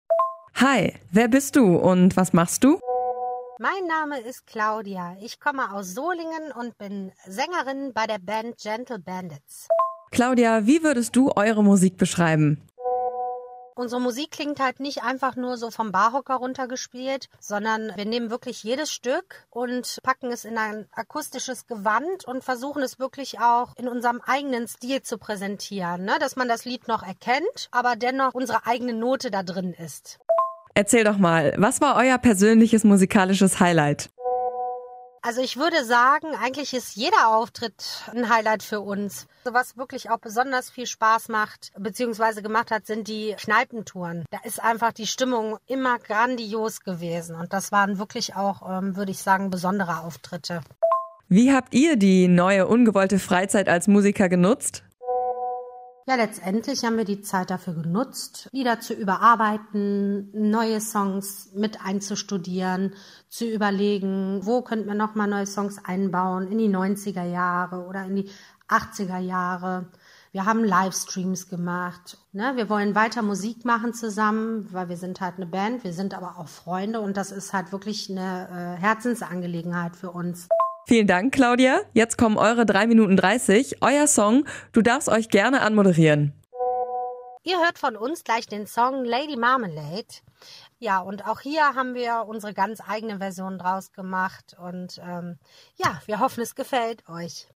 Coverband